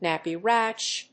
アクセントnáppy ràsh